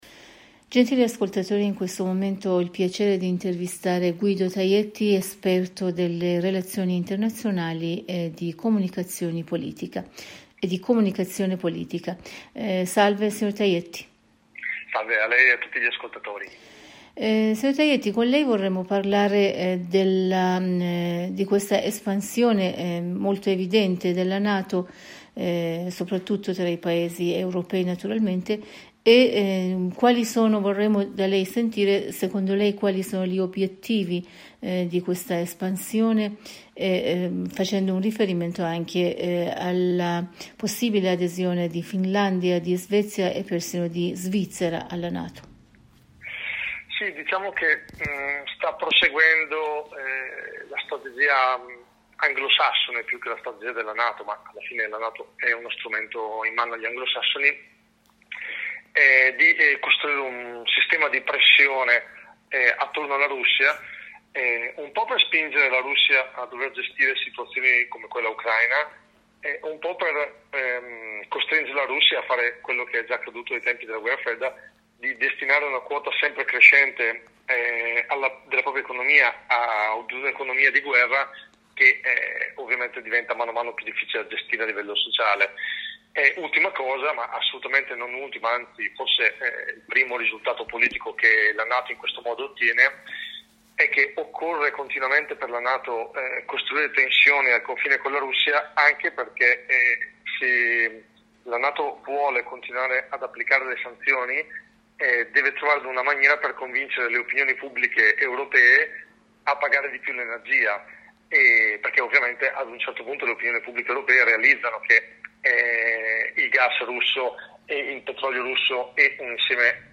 in un collegamento telefonico con la Radio Italia della Voce della Repubblica islamica dell'Iran (IRIB) con il sito http